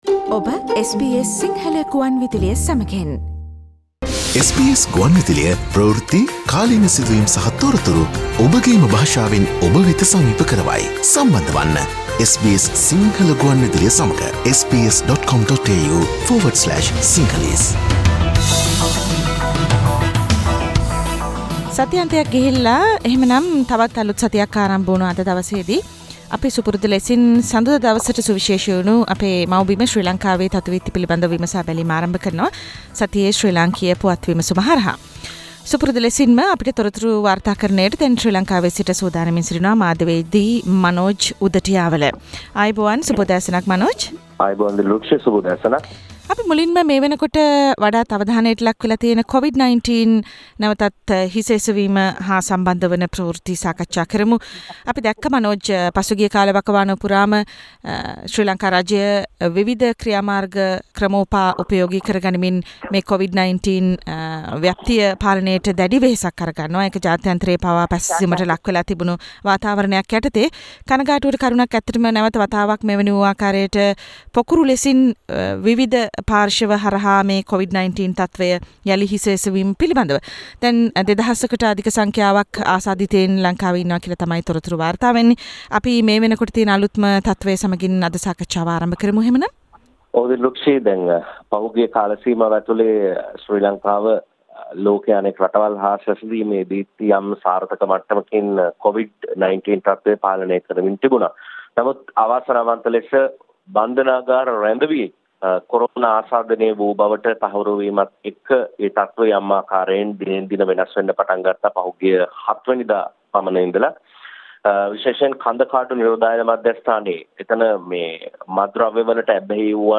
News and current affair